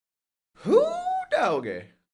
wooDoggy.mp3